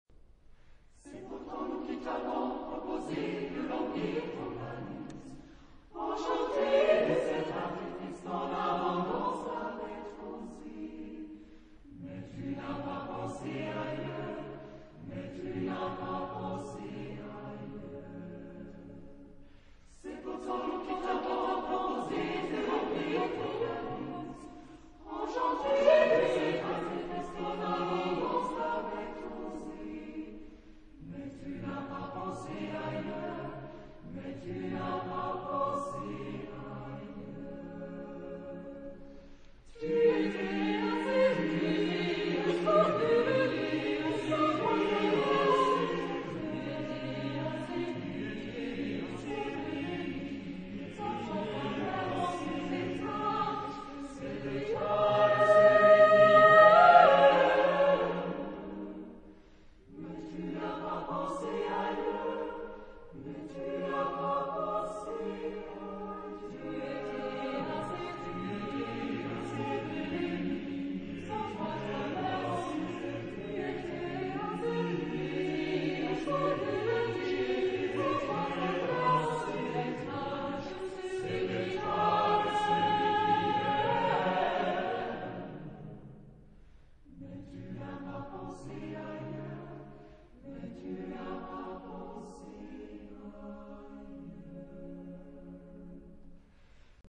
Género/Estilo/Forma: Profano ; Poético
Tipo de formación coral: SATB  (4 voces Coro mixto )